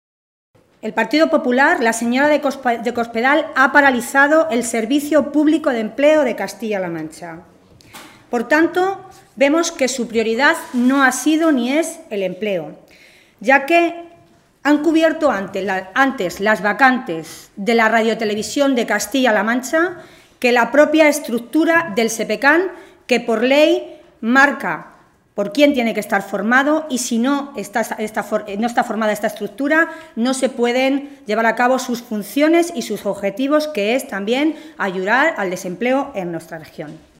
Milagros Tolón, diputada regional del PSOE de Castilla-La Mancha
Cortes de audio de la rueda de prensa